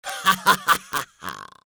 Sinister Laughs Male 02
Sinister Laughs Male 02.wav